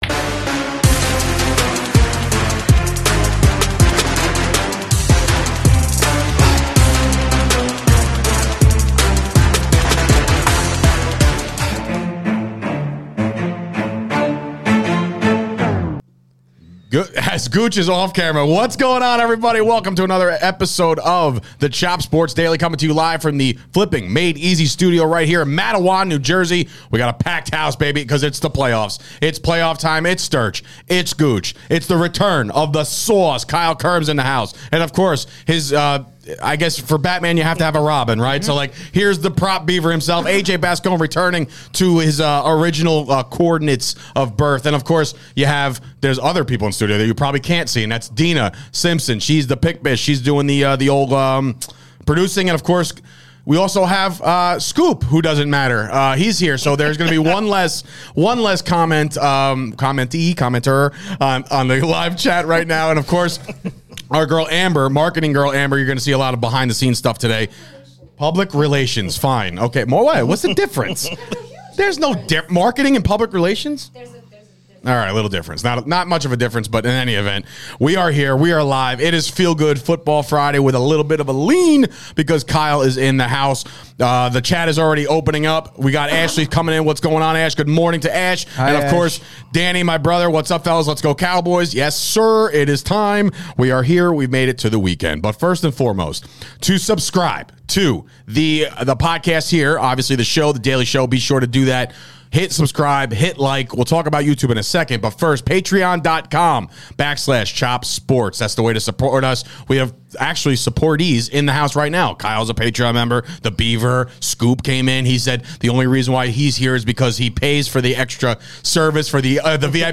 It's a jam packed studio today